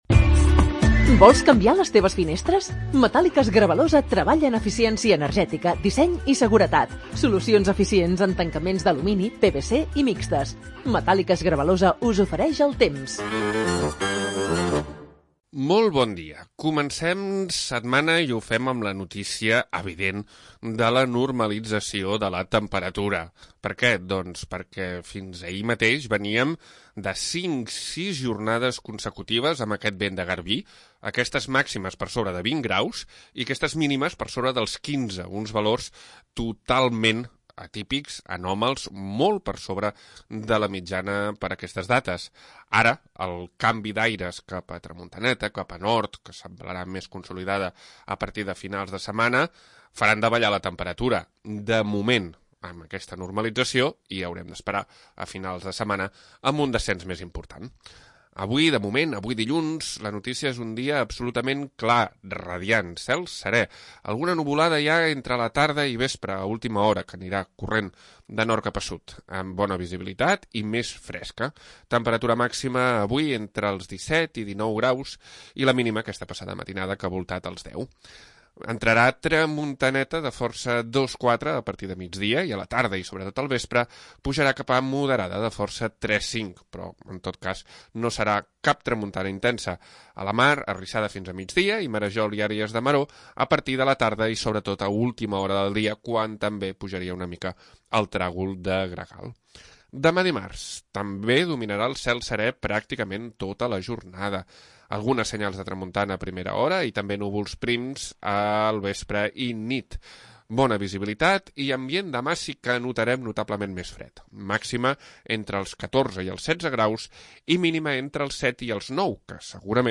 Previsió meteorològica 17 de novembre de 2025